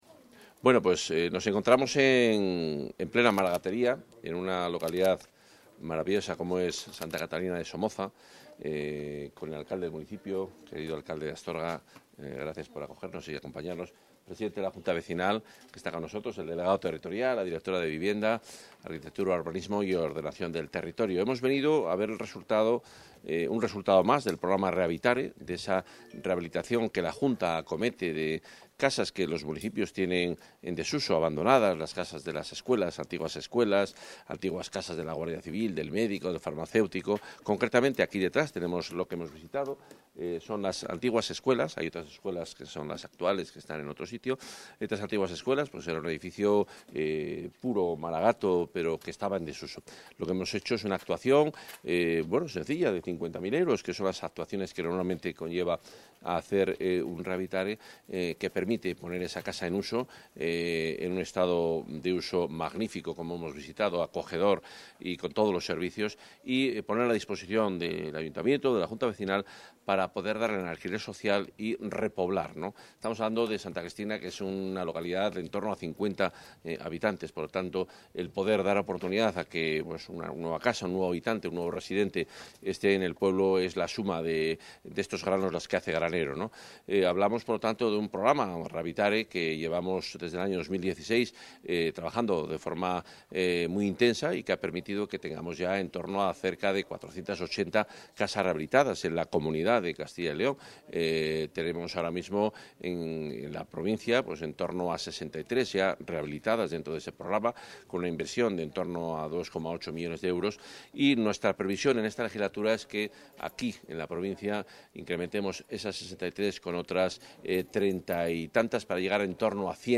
Intervención del consejero.
El consejero de Medio Ambiente, Vivienda y Ordenación del Territorio, Juan Carlos Suárez-Quiñones, ha visitado las obras de rehabilitación de una vivienda de la localidad leonesa de Santa Catalina de Somoza, enmarcadas dentro del programa Rehabitare. En la provincia de León, está previsto que se rehabiliten 36 viviendas, que se unirán al resto que prevé incorporar la Junta en este mismo territorio para aumentar el parque público, hasta un total de 278 con una inversión de 27,9 millones en esta legislatura.